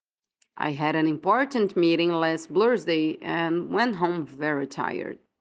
Attention to pronunciation: 🗣